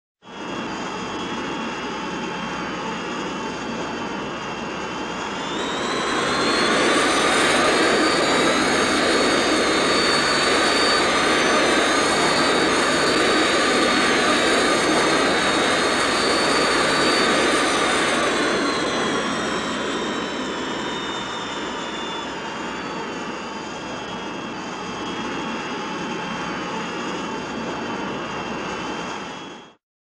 BSG FX - Viper - In flight, accelerate 02
BSG_FX_-_Viper_-_In_flight2C_accelerate_02.wav